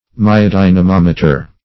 Search Result for " myodynamometer" : The Collaborative International Dictionary of English v.0.48: Myodynamometer \My`o*dy`na*mom"e*ter\, n. [Myo- + E. dynamometer.]